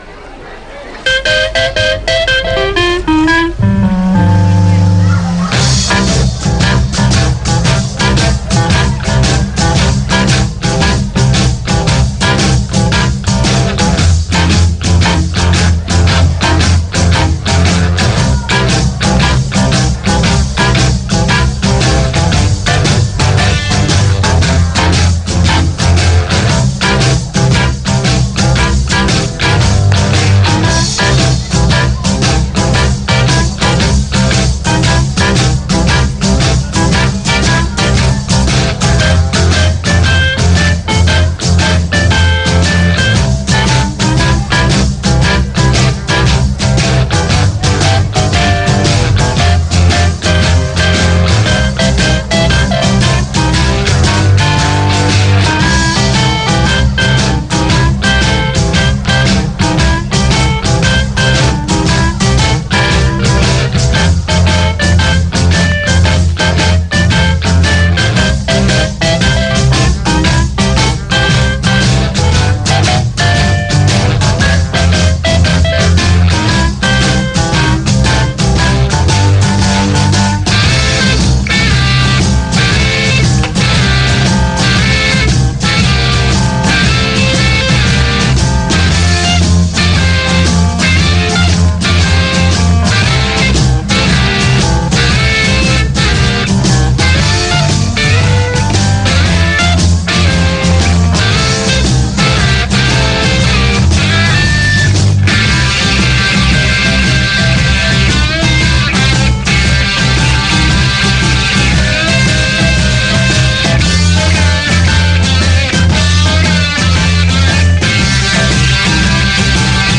Sintonia del programa, equip, salutació, tema musical, comentari sobre el que s'ha escoltat i tema musical
Musical